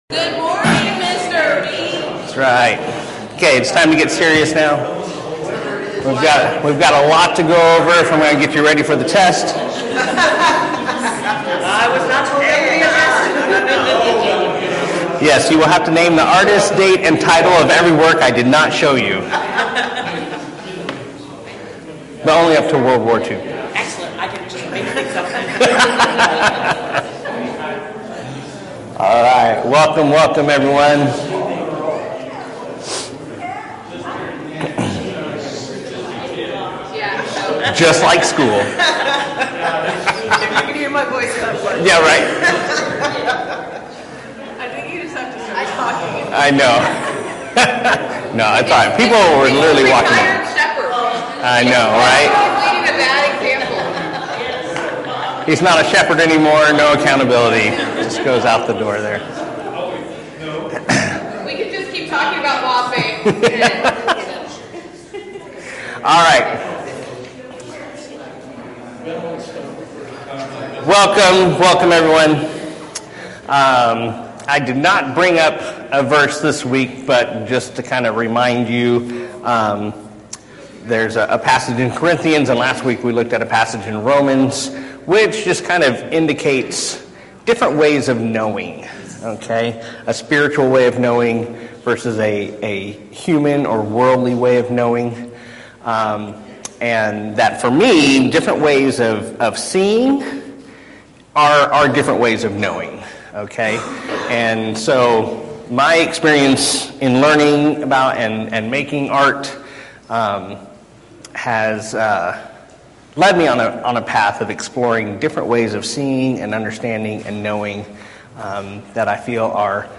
Service Type: 9:30 Hour - Class